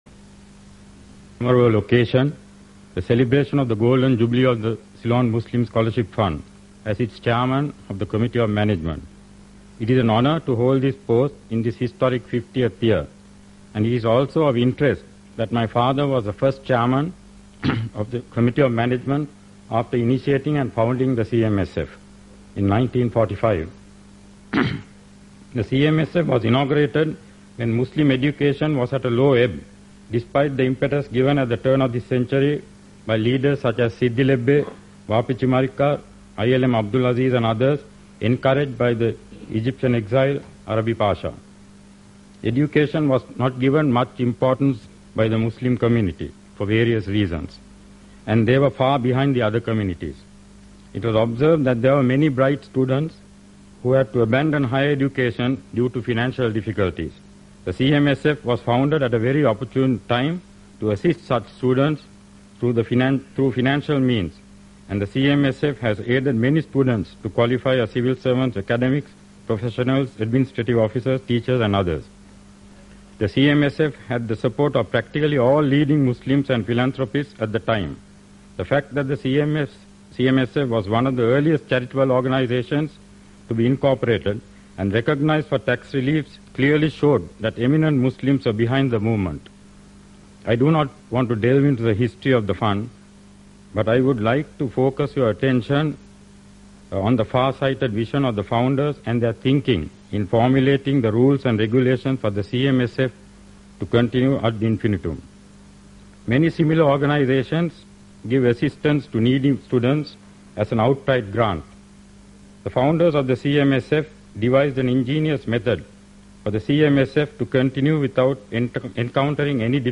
2011 in Audio Speeches